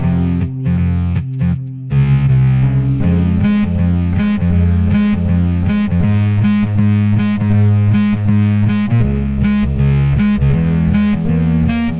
描述：数字电子低音提琴和直立式低音提琴。
Tag: 80 bpm Blues Loops Bass Guitar Loops 2.02 MB wav Key : Unknown